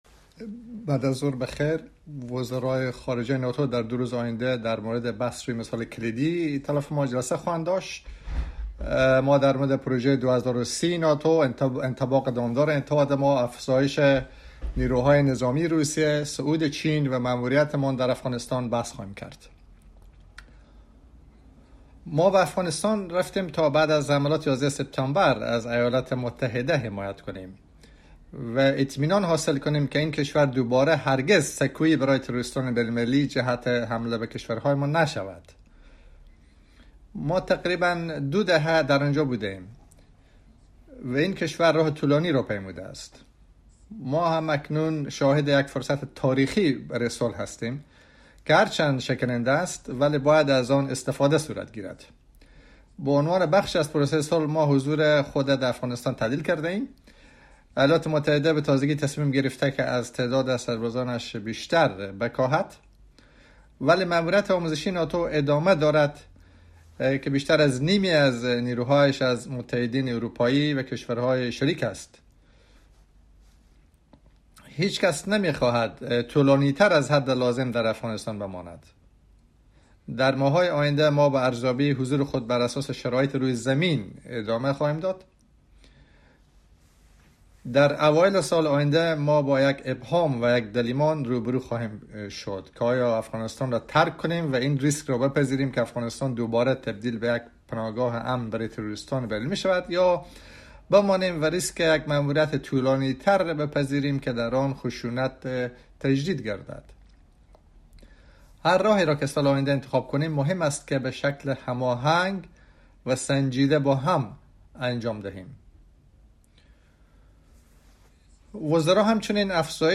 Online pre-ministerial press conference
by NATO Secretary General Jens Stoltenberg ahead of the meetings of NATO Ministers of Foreign Affairs